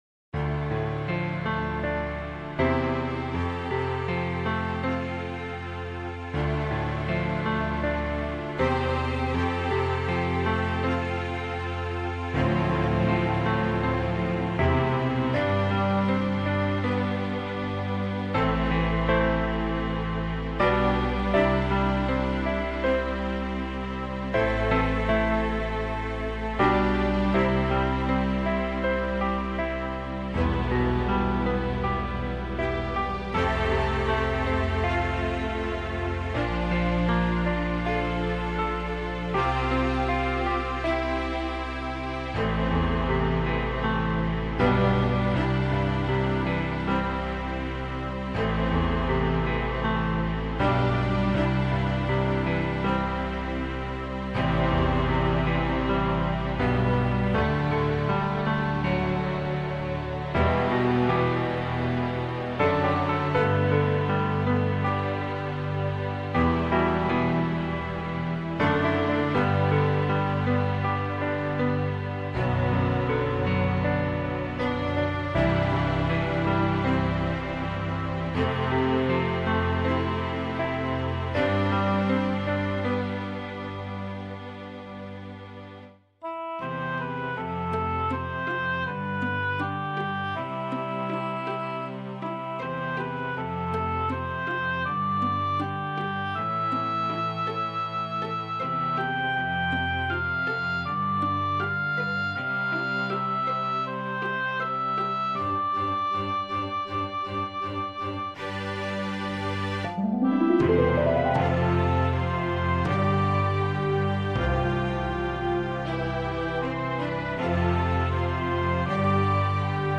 It has three parts. ABCCAB, or at least that's how I figure it. Although, if anything B is the main theme, C is the secondary and A is a intro/bridge. I'd like to think I was professional about the music, but I was really just trying to make it sound pleasant.
I will try to shrink the mp3 a little, but computer music loses respectability if it loses sound quality.